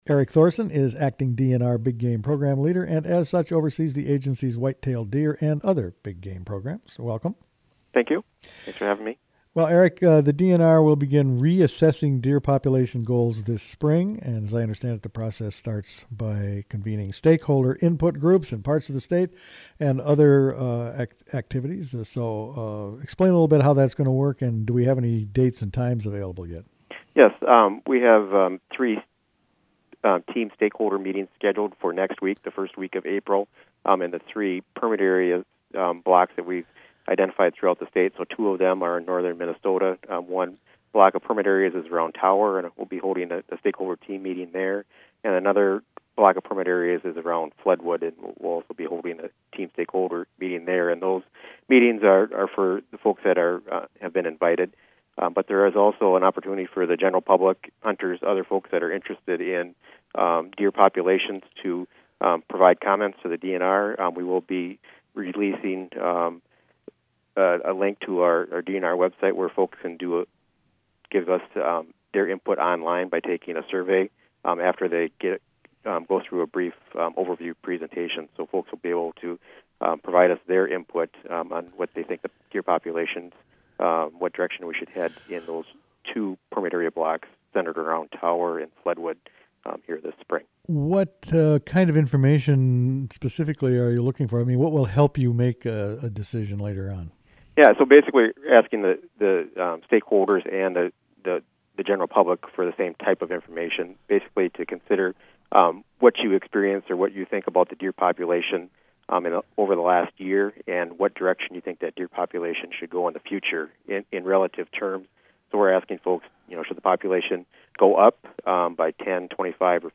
Interview: DNR to reassess state deer herd